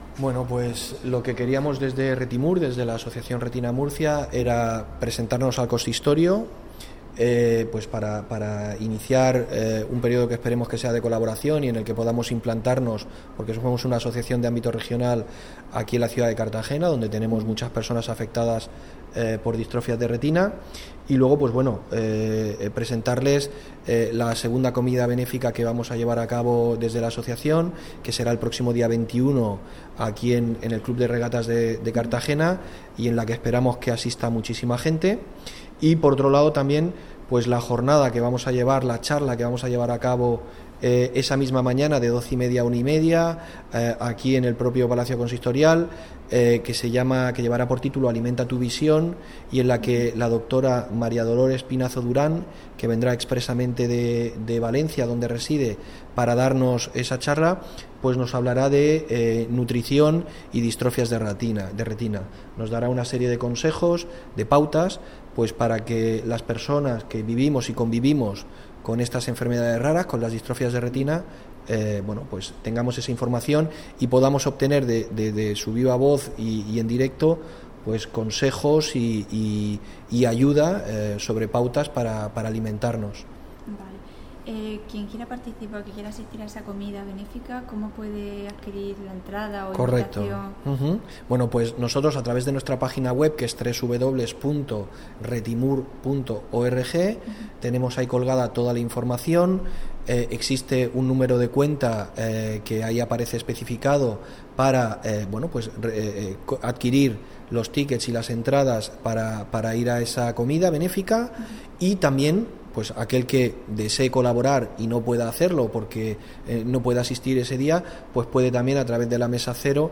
Audio: Declaraciones del alcalde en funciones, Juan Pedro Torralba (MP3 - 1,70 MB)